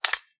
Gun sounds
gun_magazine_remove_empty_2.ogg